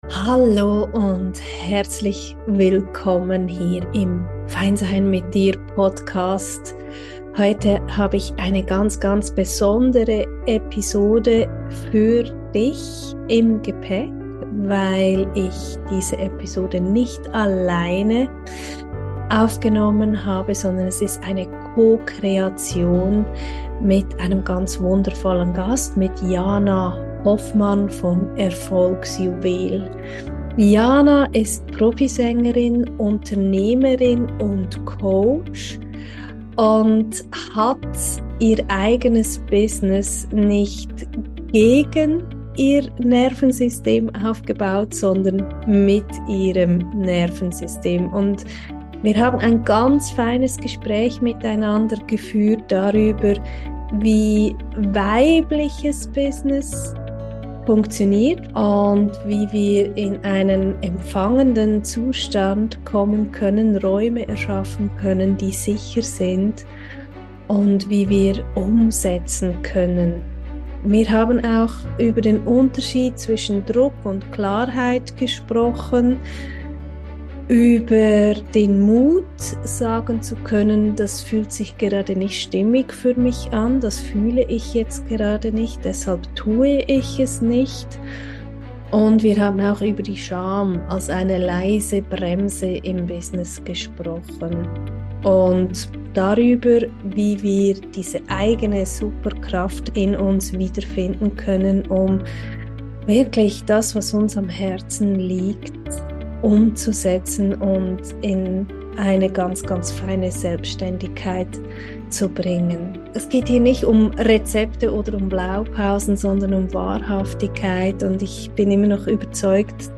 #42 - Business ganz intuitiv - Interview